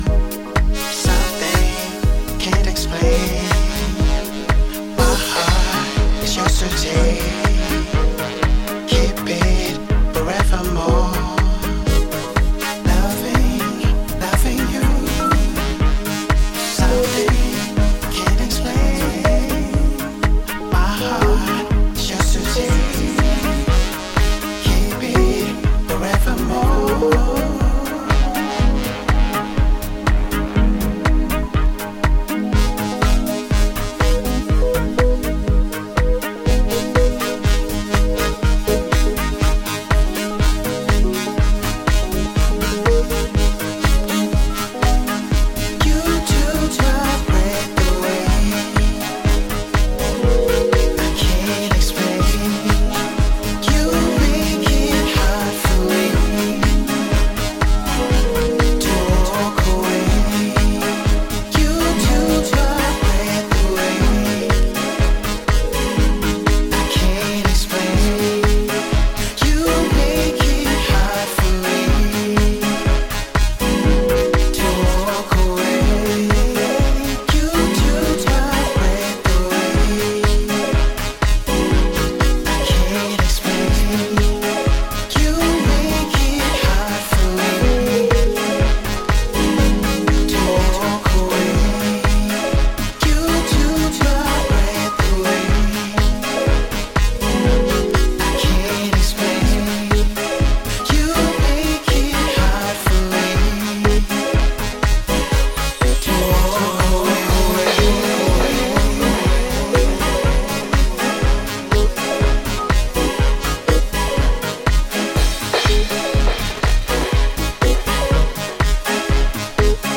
原曲のグルーヴを抑えめにしながら、より繊細でしっとり浸透していくフィーリングを強めていったそちらももちろん良し。